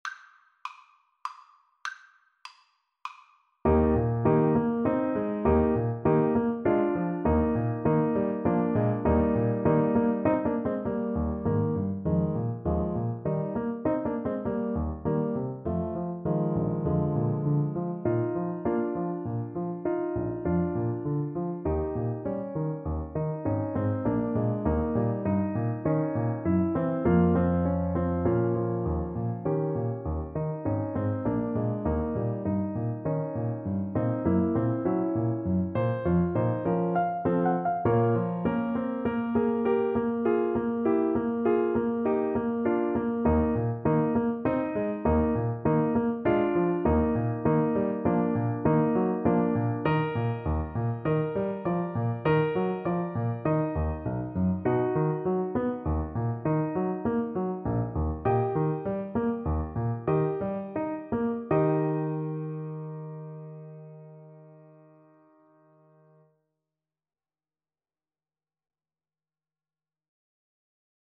Play (or use space bar on your keyboard) Pause Music Playalong - Piano Accompaniment Playalong Band Accompaniment not yet available transpose reset tempo print settings full screen
~ = 100 Allegretto grazioso (quasi Andantino) (View more music marked Andantino)
Eb major (Sounding Pitch) C major (Alto Saxophone in Eb) (View more Eb major Music for Saxophone )
3/4 (View more 3/4 Music)
Classical (View more Classical Saxophone Music)